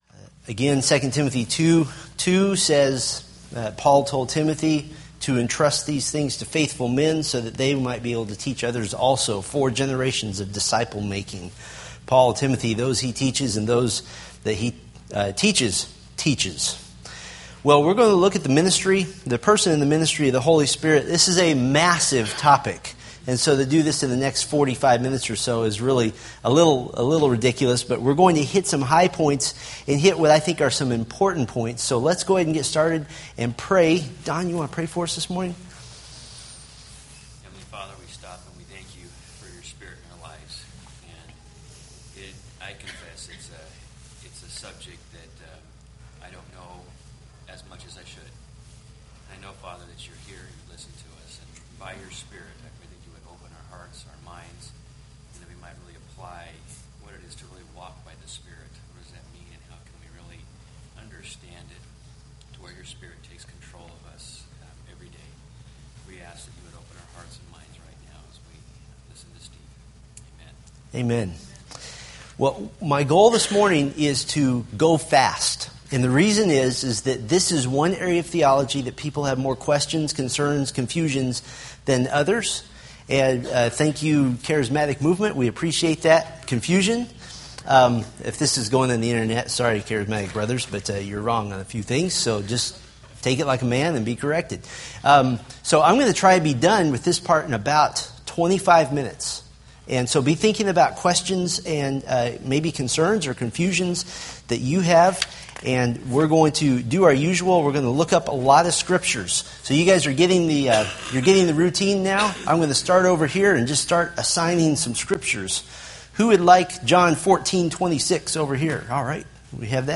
Date: Aug 4, 2013 Series: Fundamentals of the Faith Grouping: Sunday School (Adult) More: Download MP3